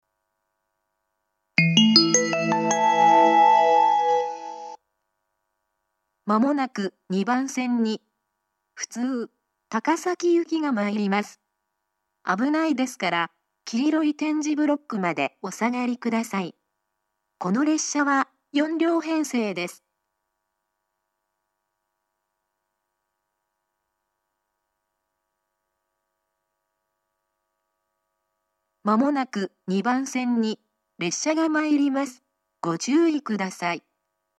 ２番線接近放送 普通高崎行（４両）の放送です。